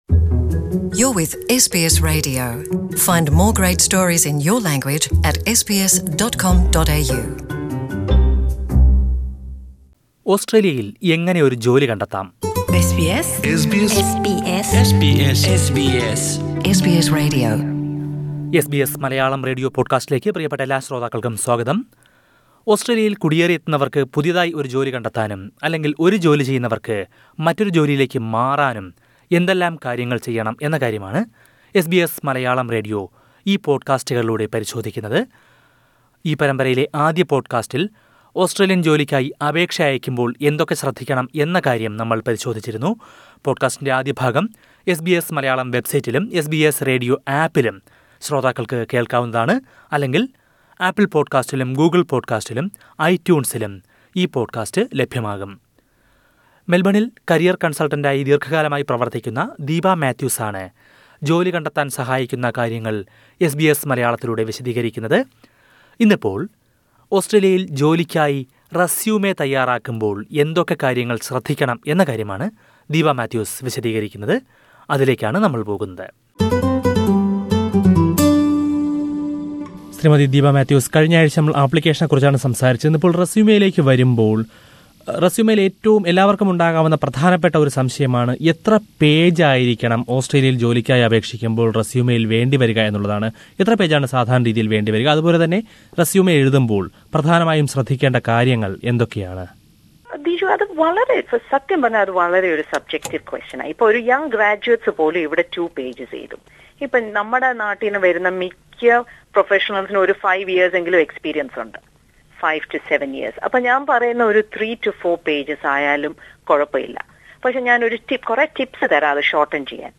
കരിയറില്‍ ഇടവേളയുണ്ടെങ്കില്‍ അത് എങ്ങനെ വിനിയോഗിച്ചു എന്ന് കൃത്യമായി എഴുതുക. കൂടുതല്‍ വിശദമായി ഇക്കാര്യങ്ങള്‍ അറിയാന്‍ അഭിമുഖം കേള്‍ക്കുക..